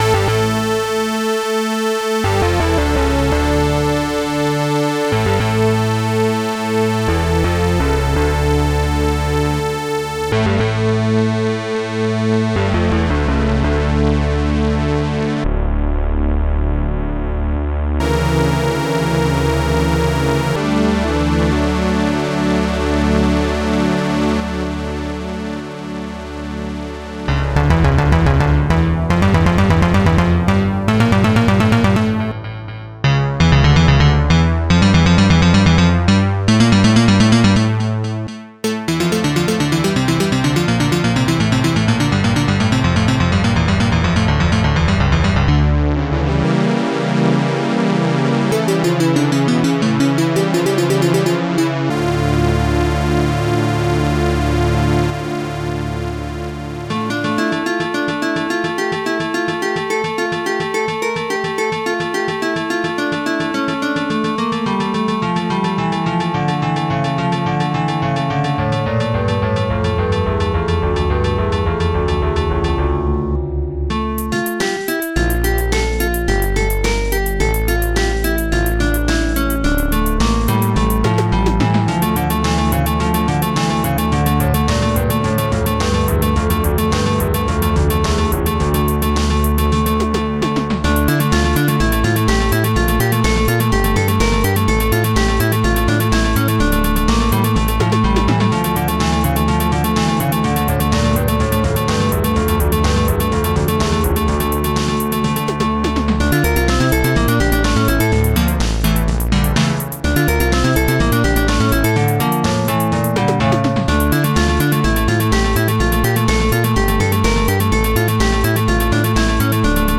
st-01:strings8 st-02:tallic st-01:polysynth st-02:reflex st-02:guitar1 st-01:bassdrum2 st-02:mysnare1 st-01:hihat2 st-01:popsnare1 st-01:strings3 st-01:organ